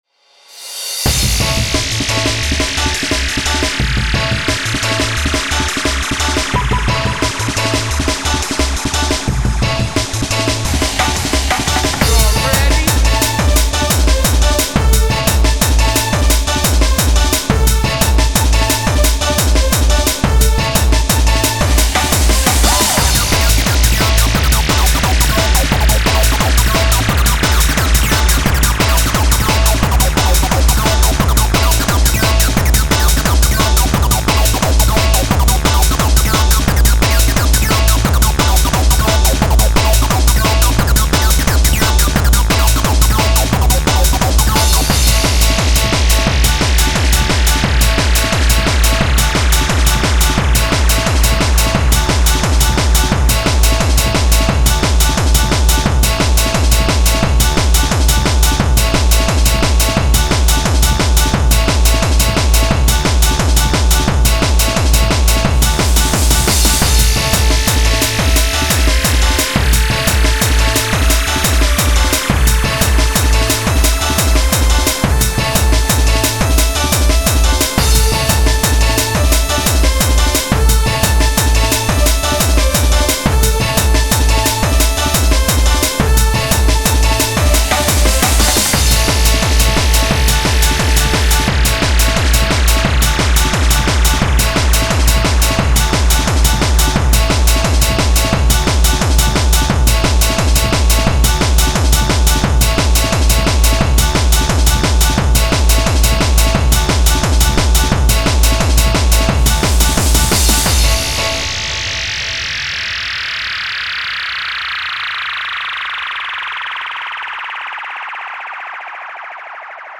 a mix of happy hardcore and jungle, awh yeah